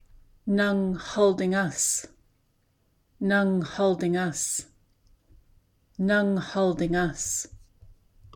In Northern Haida there is X̱aad Kíl, also known as the Old Massett dialect of the Haida language.